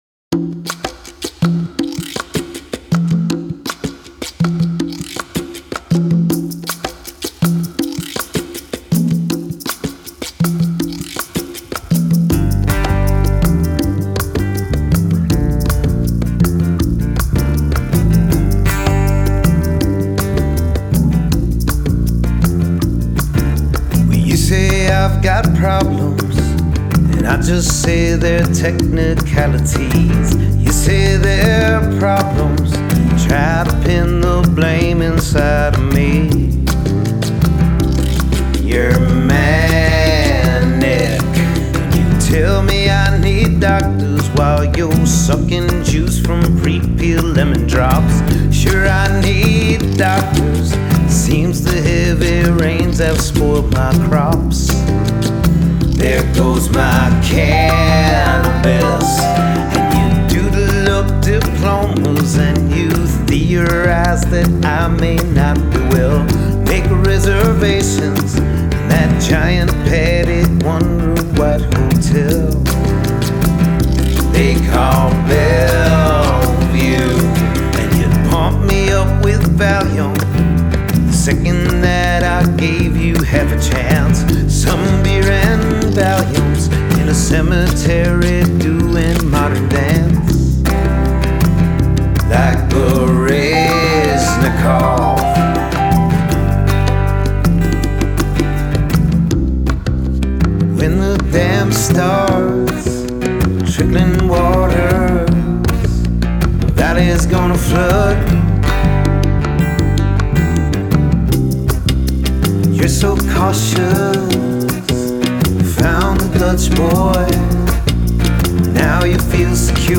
Pop / Rock